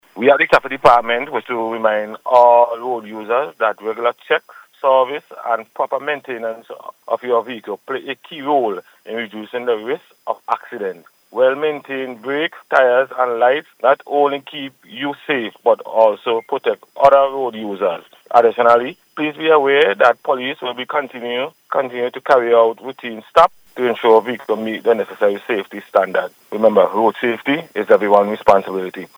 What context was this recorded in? He made the appeal during the Traffic Highlights programme aired on NBC Radio.